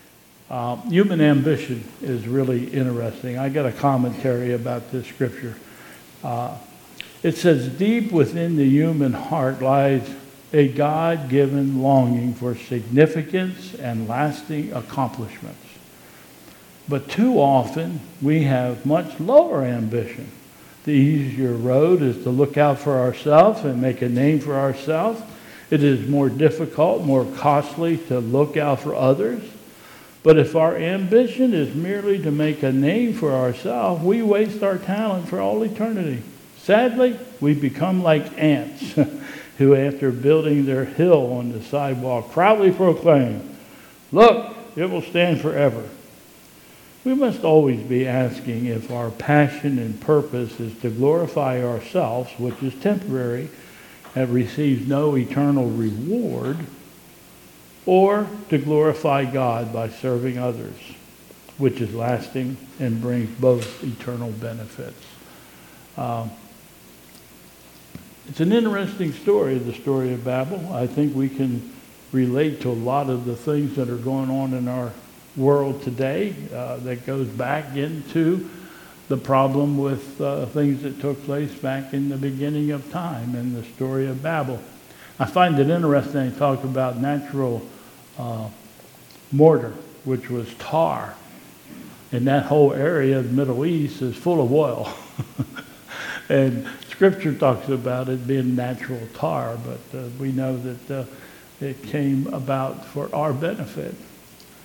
2022 Bethel Covid Time Service
OT Reading: Genesis 11:1-9 and